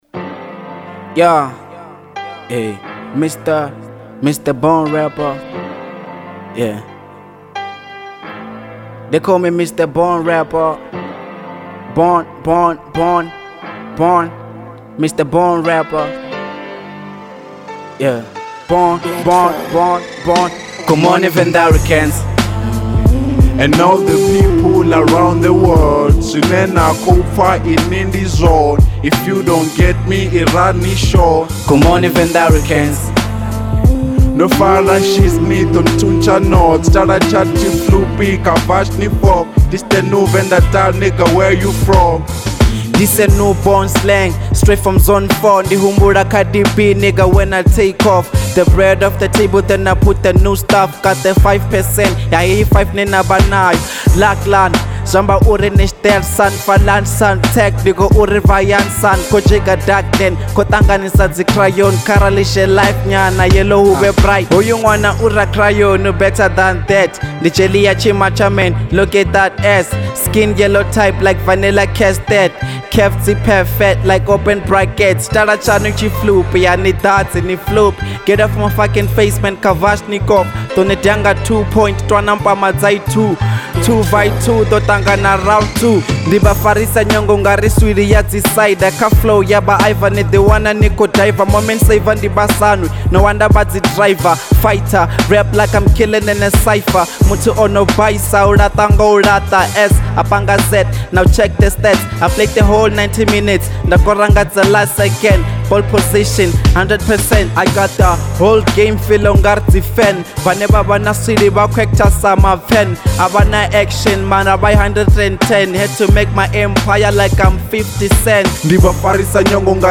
03:42 Genre : Venrap Size